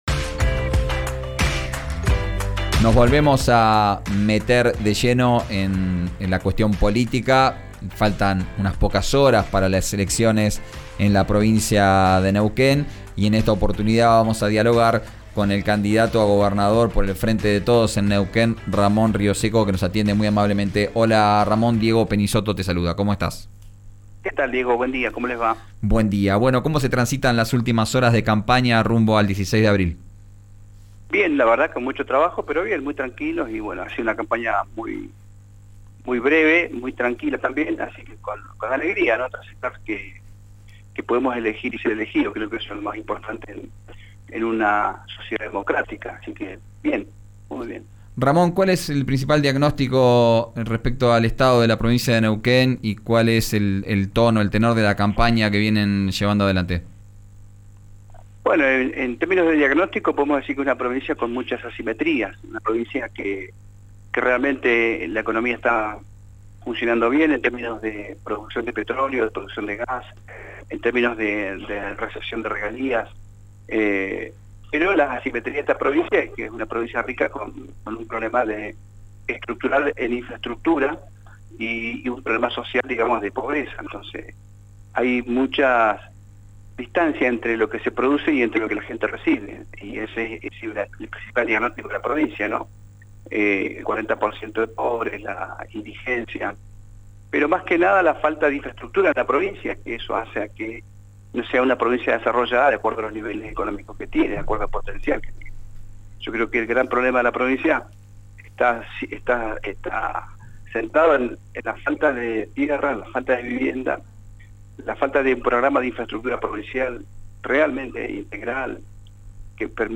El candidato a gobernador por el Frente de Todos Neuquino, Ramón Rioseco, dialogó esta mañana con RÍO NEGRO RADIO y planteó sus principales propuestas para la elección del 16 de abril. Dijo que hoy la renta petrolera que queda para Neuquén «es paupérrima» e insistió con su plan para subir las regalías del 12% al 24%. También cuestionó el actual sistema de coparticipación municipal y anticipo un proyecto para hacer «sustentable» la caja jubilatoria del ISSN.